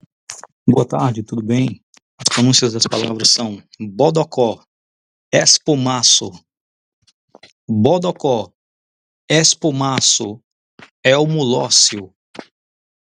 Me envia em 320 kbp/s sem Compressor e Limiter (Flat) por favor.
A palavra Bodocó se pronuncia como se tivesse acento agudo em todos os Ós.